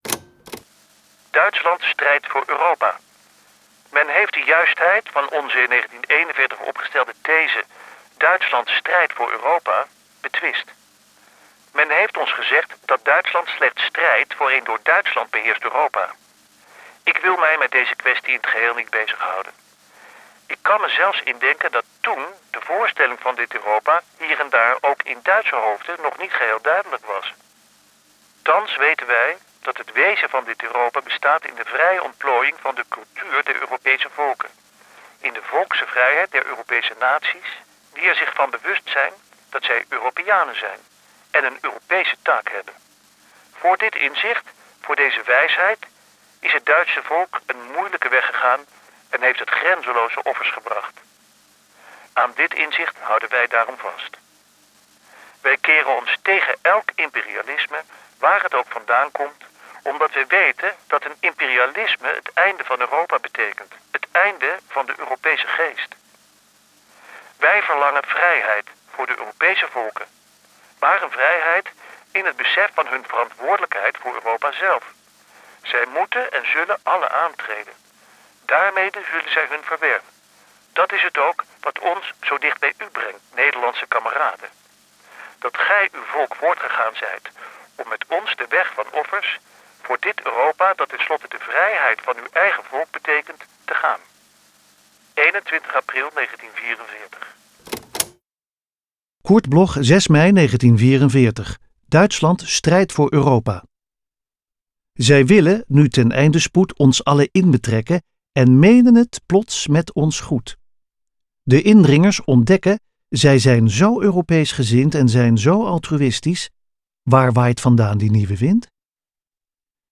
Aufnahme: Karaktersound, Amsterdam · Bearbeitung: Kristen & Schmidt, Wiesbaden